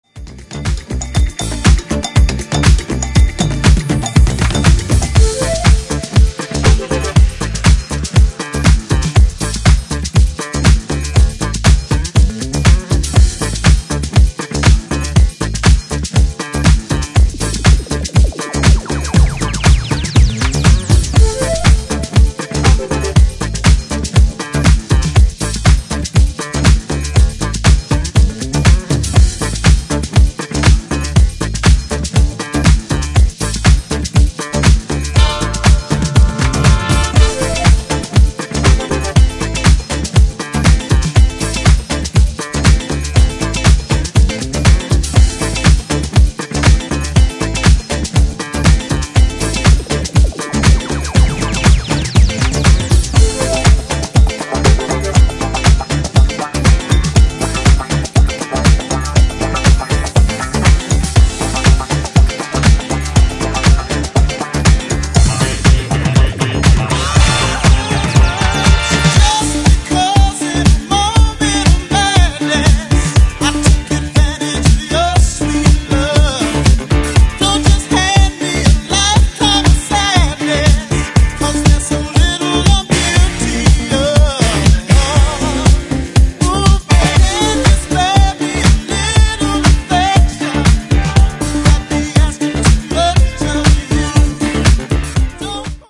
ブギーなディスコ・ハウスで盛り上がり間違いありません！！
ジャンル(スタイル) DISCO HOUSE / RE-EDIT